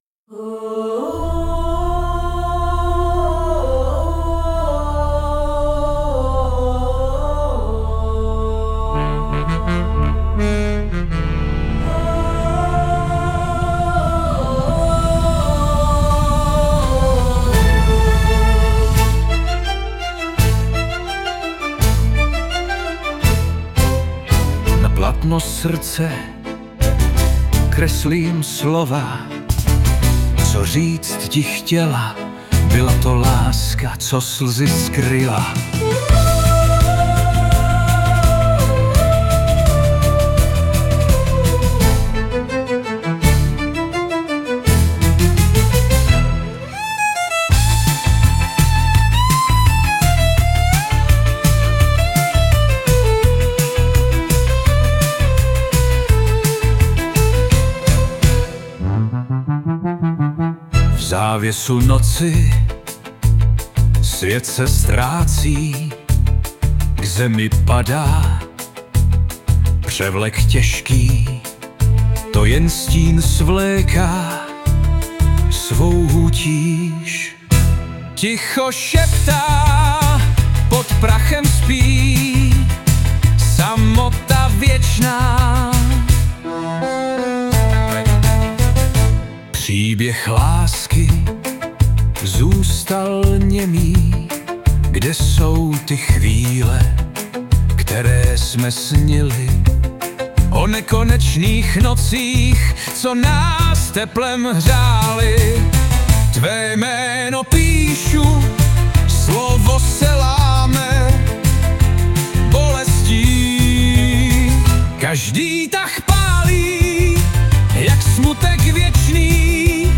2025 & Hudba, zpěv a obrázek: AI
Ale aspoň jsem tam hodil o něco veselejší hudbu, ale i tak vyzněla trochu divně:-)